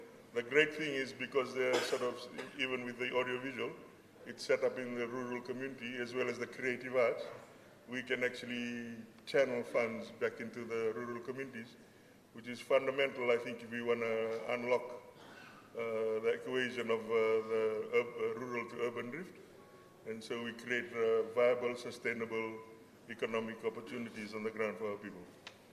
This has been highlighted at the National Economic Summit underway in Suva.
Trade Minister, Manoa Kamikamica.